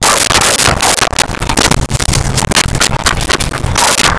Dog Barking 02
Dog Barking 02.wav